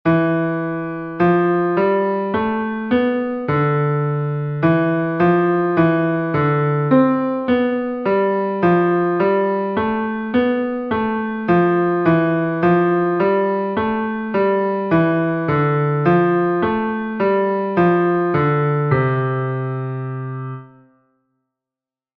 keeping the beat exercise 7